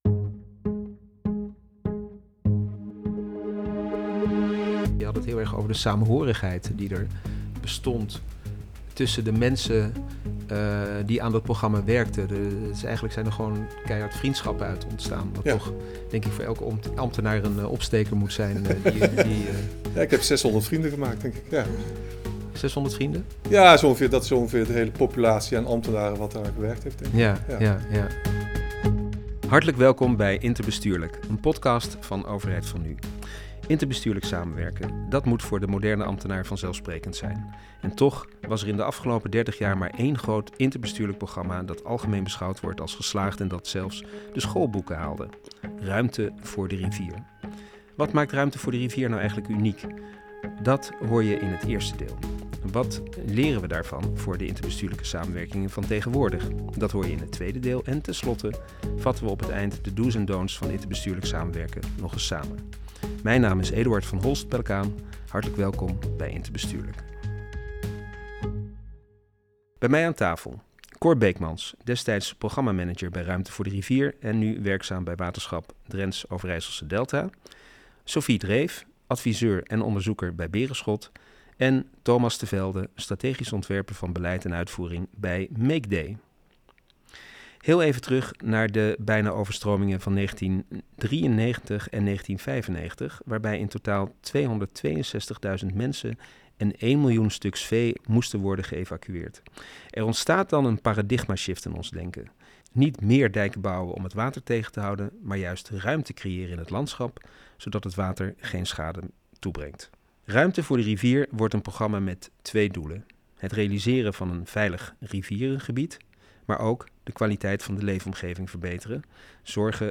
Beeld: © EMMA Podcastopname ‘Interbestuurlijk’, 14 november 2024 bij EMMA, Den Haag.